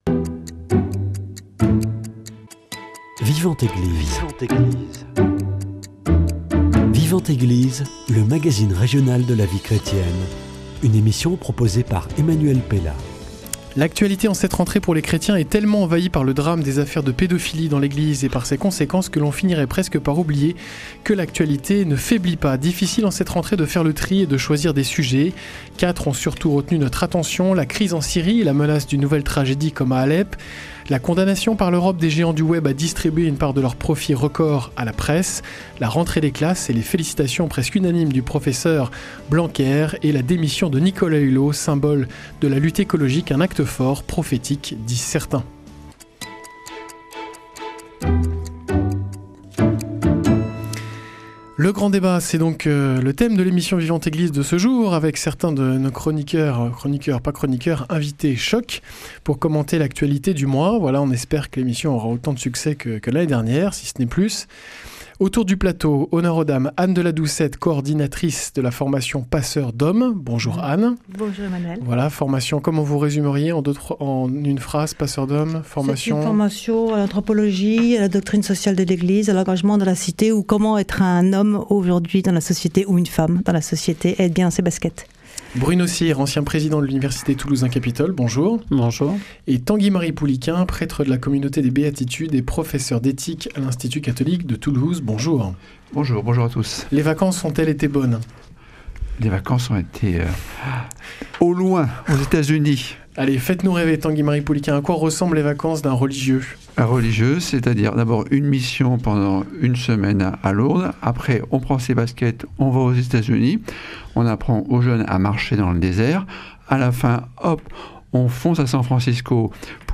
Le grand débat